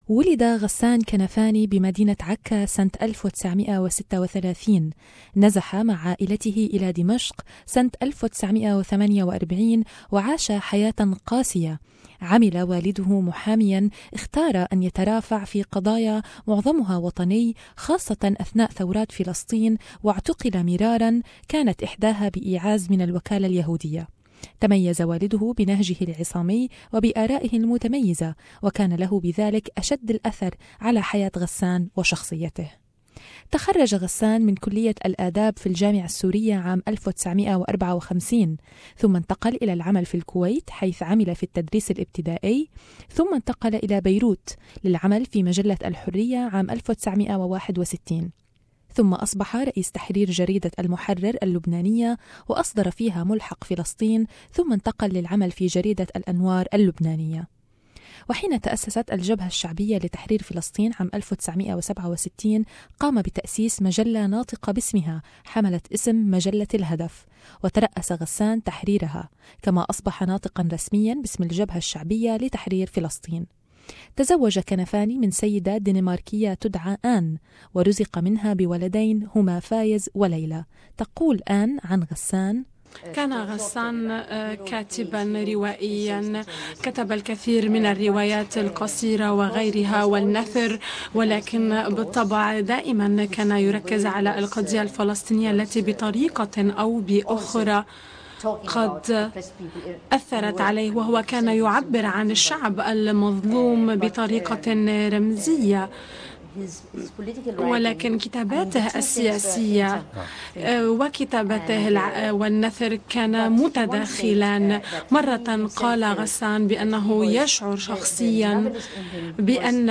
هذا التقرير